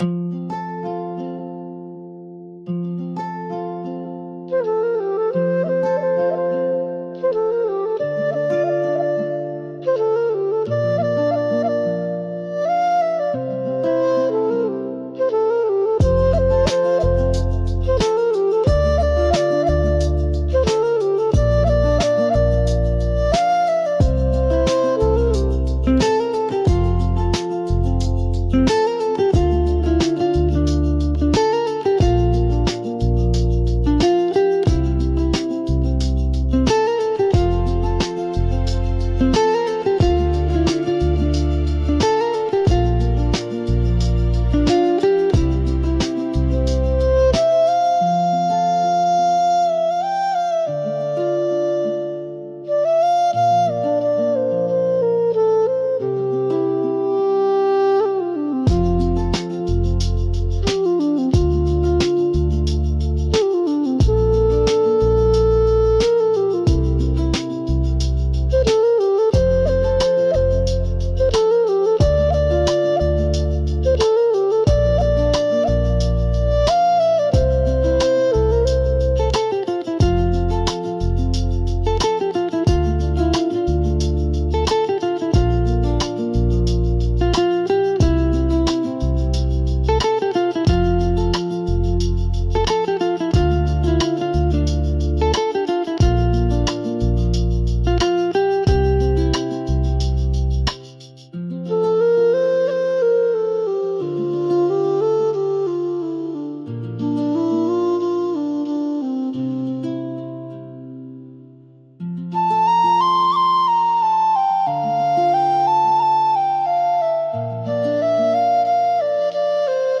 F Major – 90 BPM
Bollywood
Chill